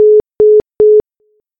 busy-signal.61ef2d69.mp3